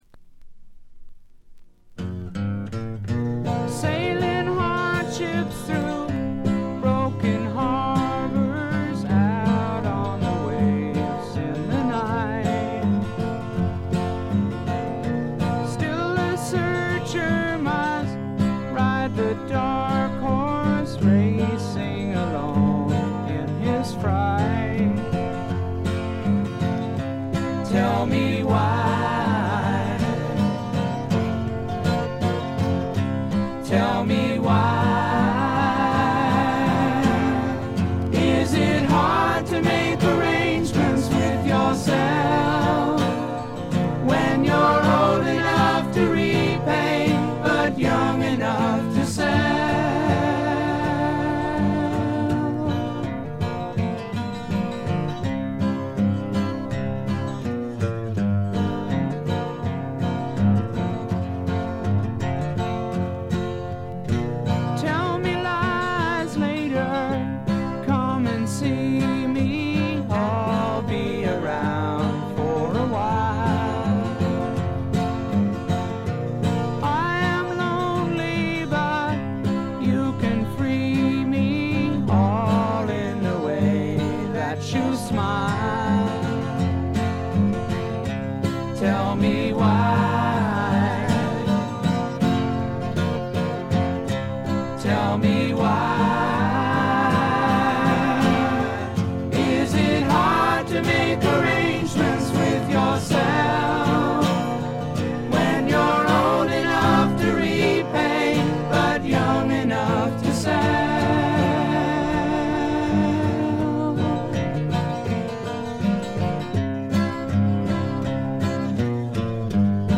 チリプチ少々。散発的なプツ音3−4回程度。
試聴曲は現品からの取り込み音源です。
guitar, vocal